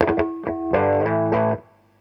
RIFF1.wav